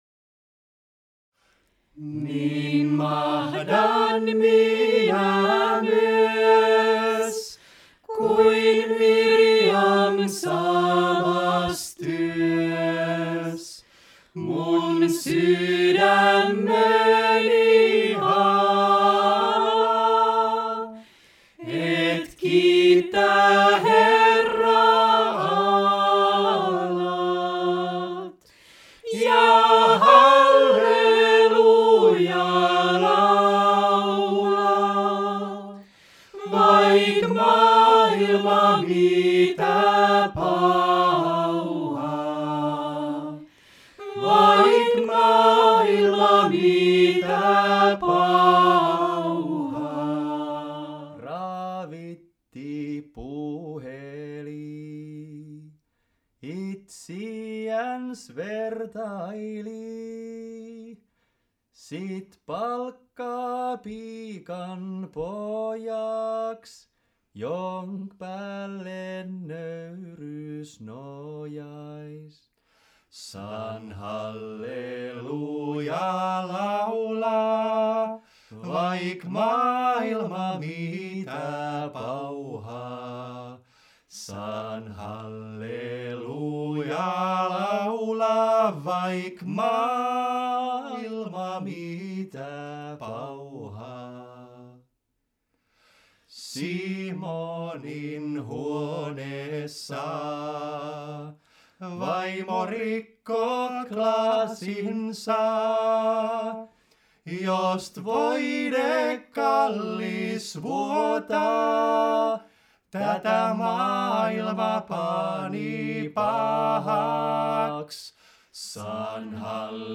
Kävimme kurssilla läpi suomalaisen virren ja kansanveisuun historiaa ja opettelimme kansanomaista veisuutyyliä, jolla tulevan esityksemme halusin värittää.
Esitys on kooste erilaisista toteutustavoista: perinteinen melodia/
neliääninen sovitus käsikirjoituksesta/ improvisointia perinteiseen sävelmään/ perinteinen sävelmä.